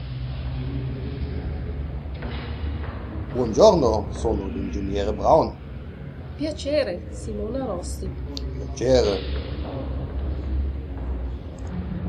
In jeder Übung sind Muttersprachler zu hören, die sich mit in einer Fremdsprache sprechenden Personen unterhalten.